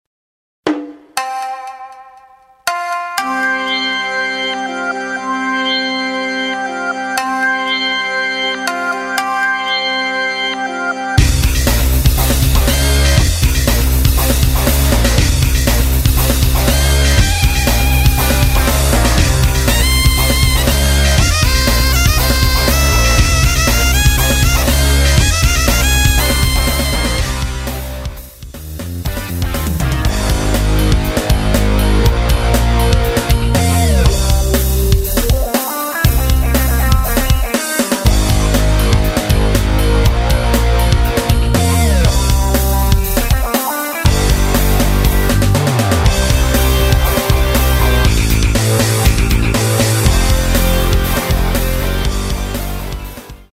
MR입니다.
원곡의 보컬 목소리를 MR에 약하게 넣어서 제작한 MR이며